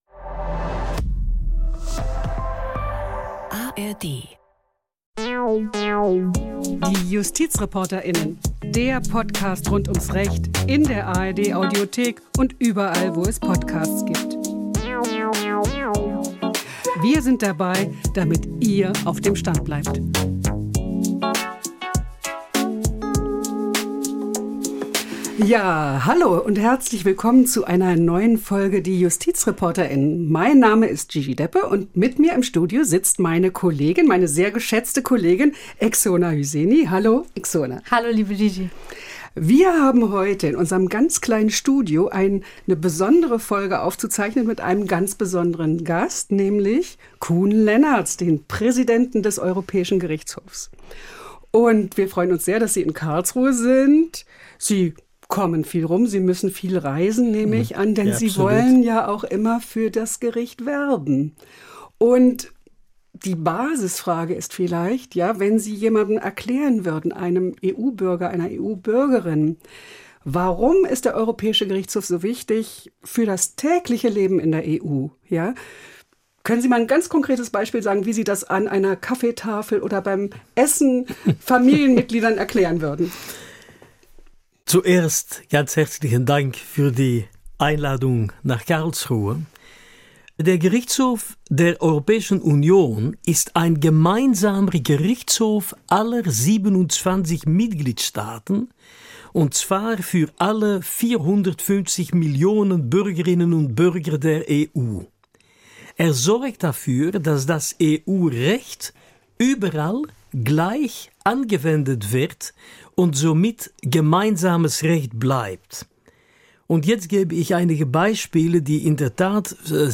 Beschreibung vor 5 Monaten Koen Lenaerts, der Präsident des Europäischen Gerichtshofs, war zu Besuch in der ARD-Rechtsredaktion in Karlsruhe.